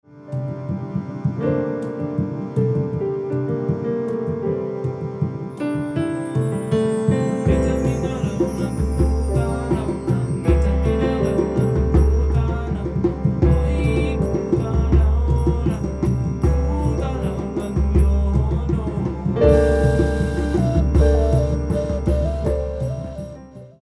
two beats